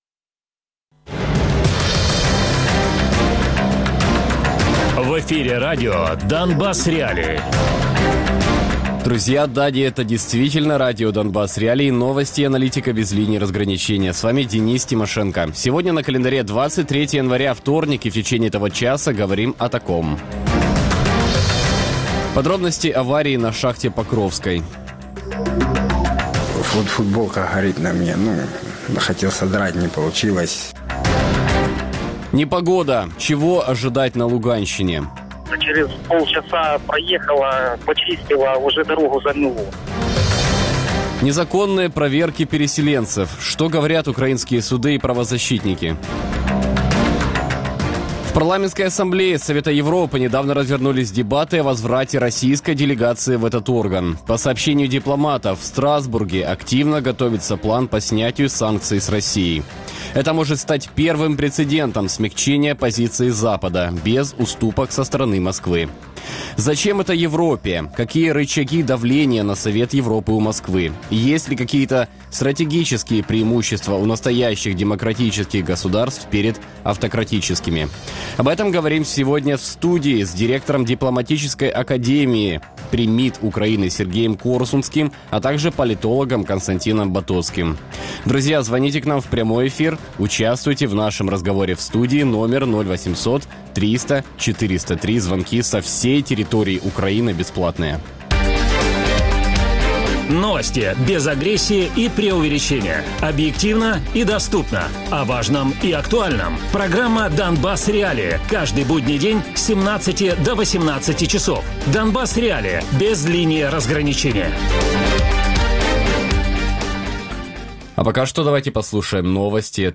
Радіопрограма